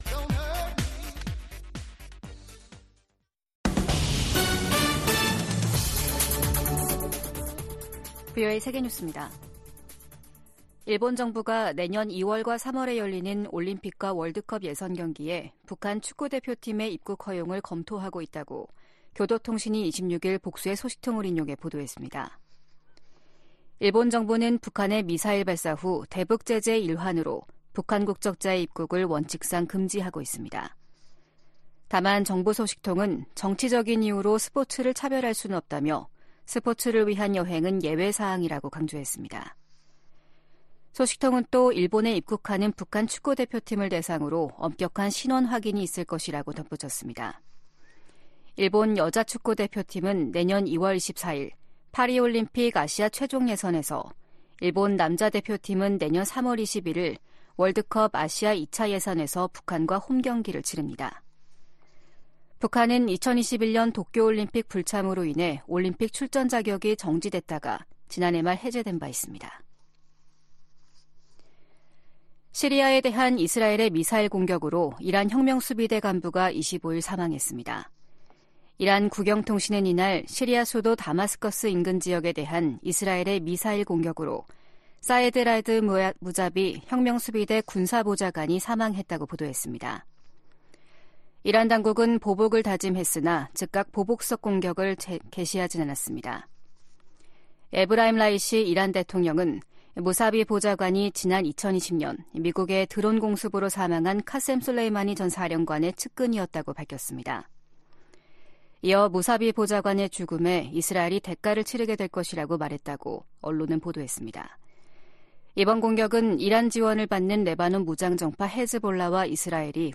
VOA 한국어 아침 뉴스 프로그램 '워싱턴 뉴스 광장’ 2023년 12월 27일 방송입니다. 조 바이든 대통령이 서명한 2024회계연도 미 국방수권법안에는 주한미군 규모를 현 수준으로 유지하는 내용과 한반도 관련 새 조항들이 담겼습니다. 북한이 영변의 실험용 경수로를 완공해 시운전에 들어간 정황이 공개되면서 한국 정부가 관련 동향을 예의주시하고 있습니다. 유엔난민기구가 중국 정부에 중국 내 탈북민의 열악한 인권 실태를 인정하고 개선할 것을 권고했습니다.